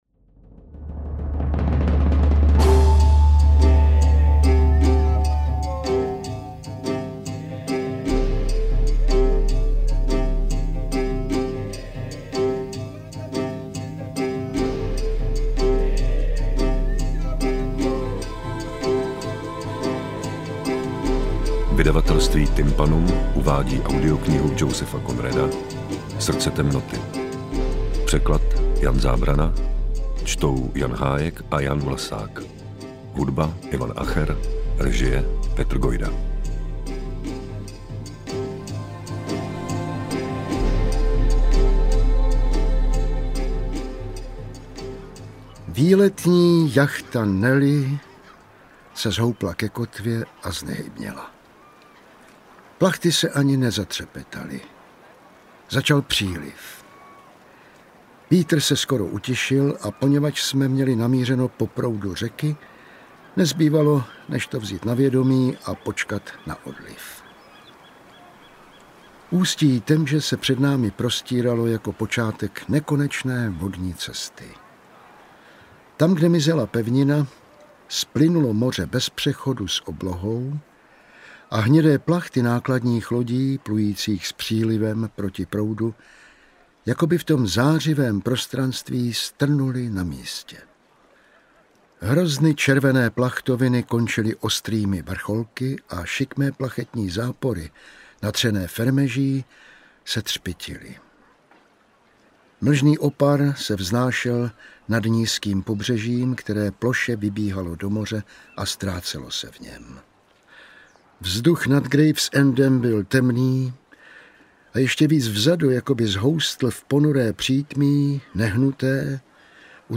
Interpreti:  Jan Hájek, Jan Vlasák
AudioKniha ke stažení, 14 x mp3, délka 4 hod. 39 min., velikost 254,8 MB, česky